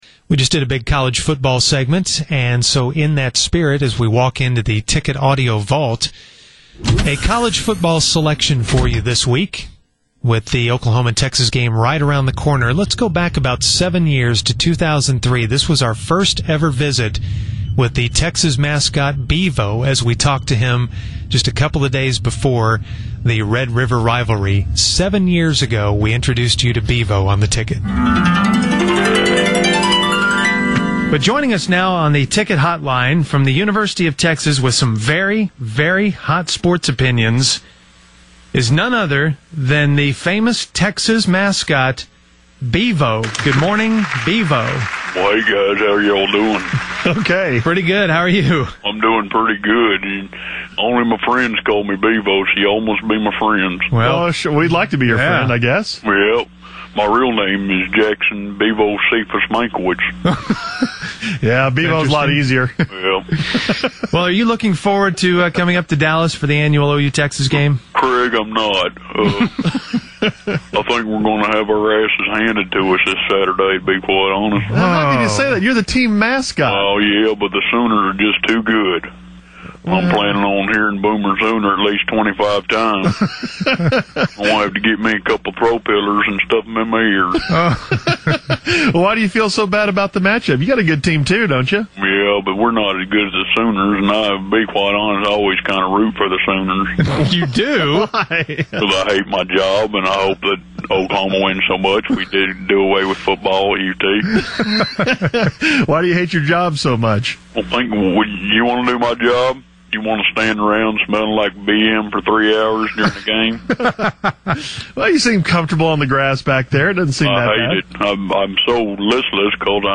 They found this classic interview